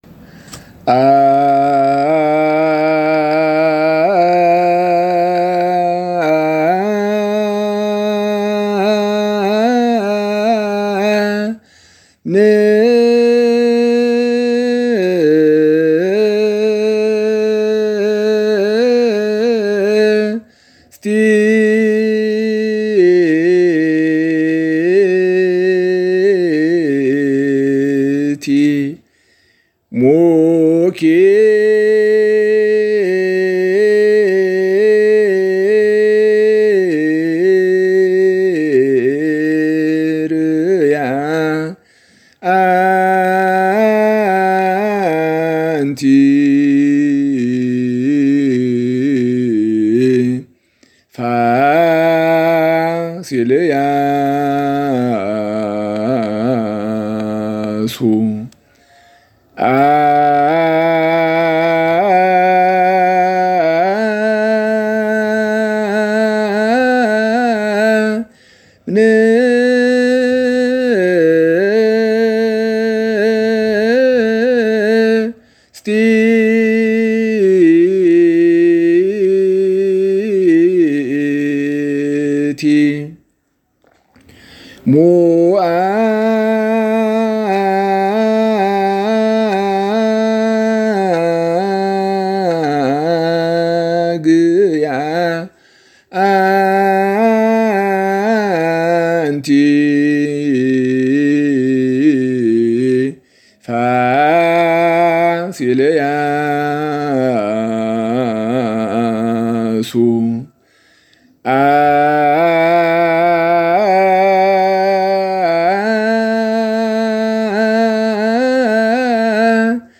ልዩ የስቅለት ዜማ